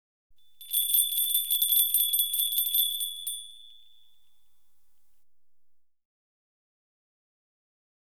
Download Bells sound effect for free.
Bells